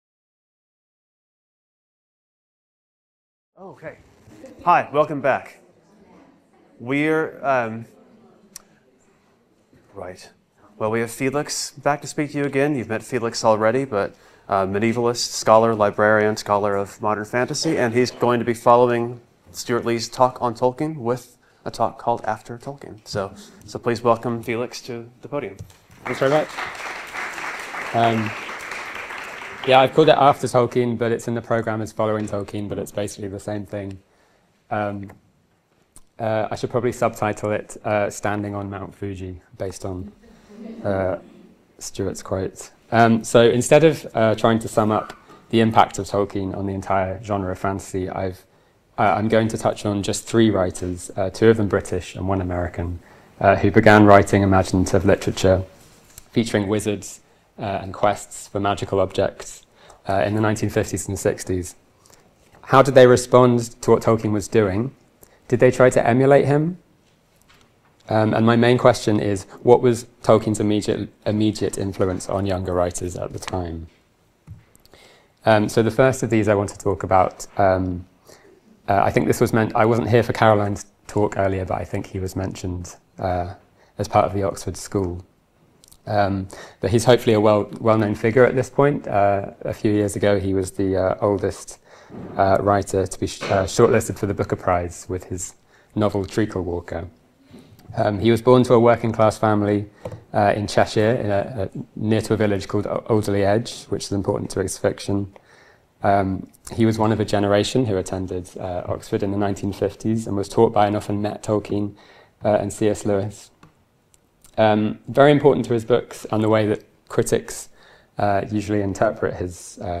Short talk (10 minutes) - Part of the Bloomsbury-Oxford Summer School (23rd-25th September 2025) held at Exeter College.